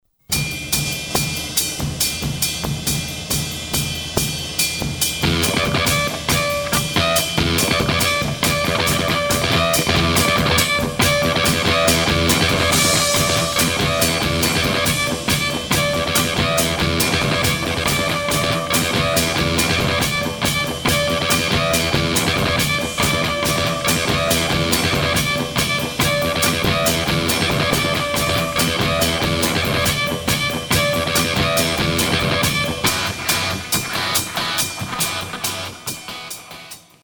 uncovered guitar layer